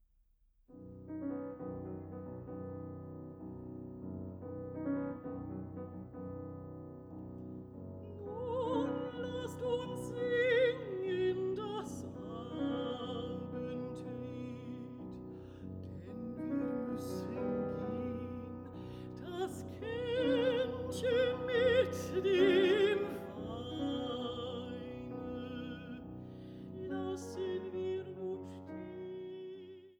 Mezzosopran
Klavier